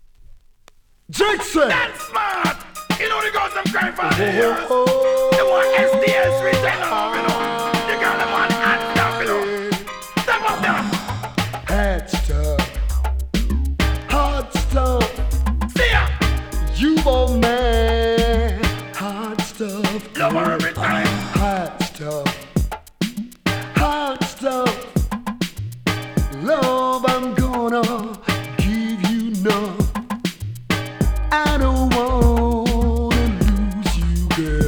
REGGAE 90'S